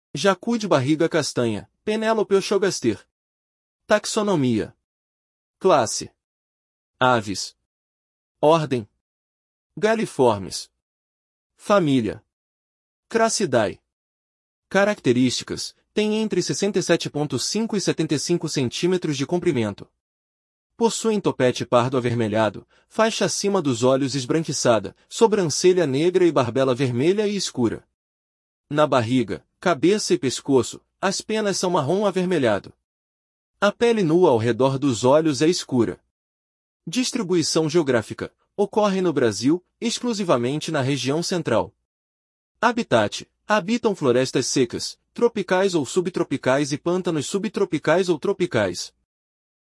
Jacu-de-barriga-castanha (Penelope ochrogaster)